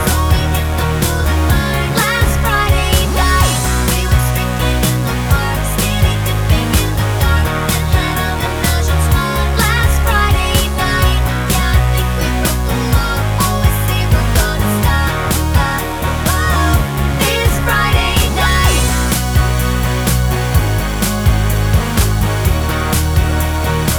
No Saxophone Solo Pop (2000s) 3:51 Buy £1.50